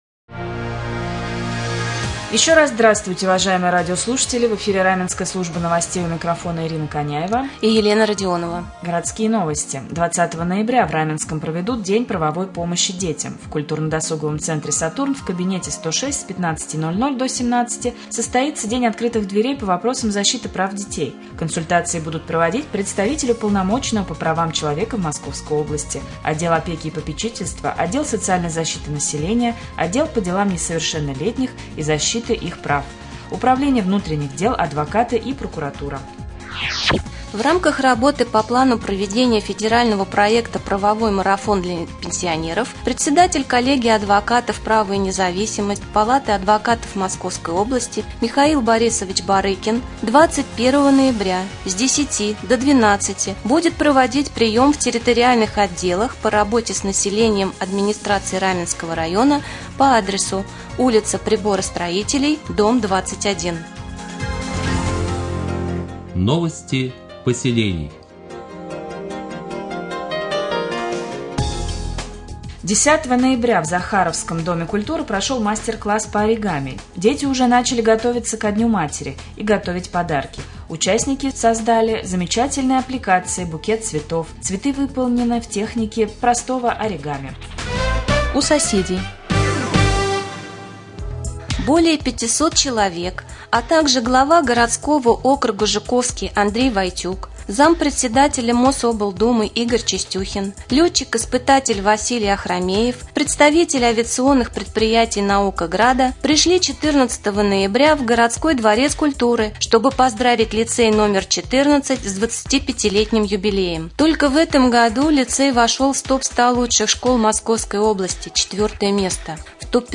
Сегодня в новостном выпуске на Раменском радио Вы узнаете, когда в Раменском проведут День правовой помощи детям, где в Раменском пройдет прием населения в рамках правового марафона для пенсионеров, а также последние областные новости и новости соседних районов.